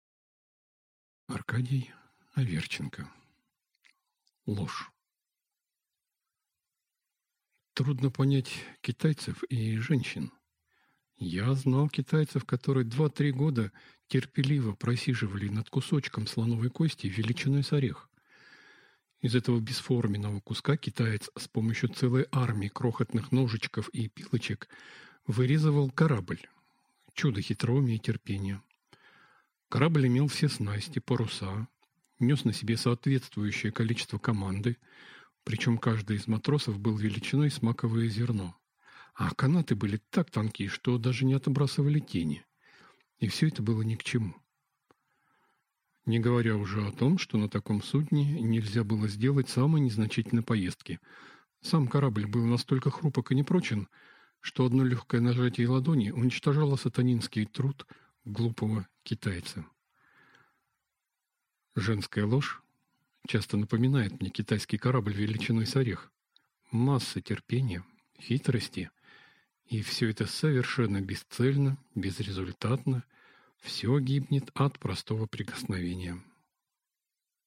Аудиокнига «Забери меня домой».